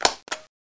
00121_Sound_button.mp3